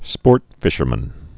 (spôrtfĭshər-mən)